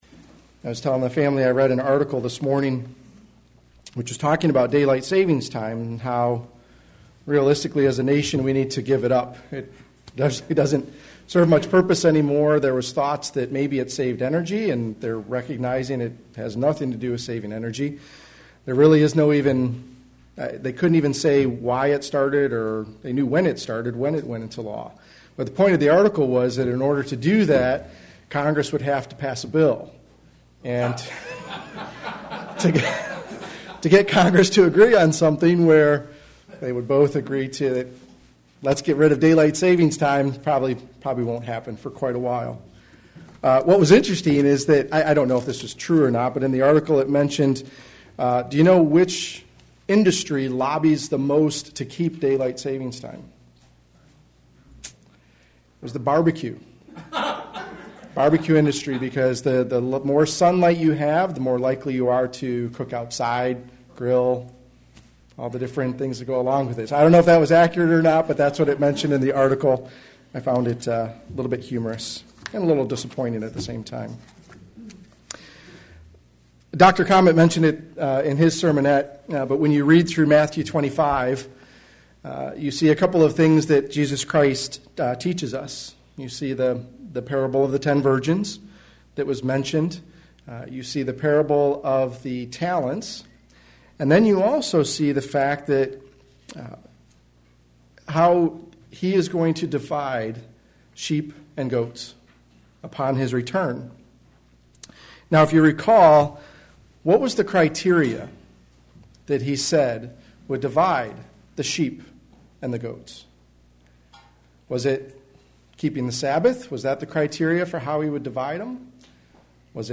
Sermons
Given in Flint, MI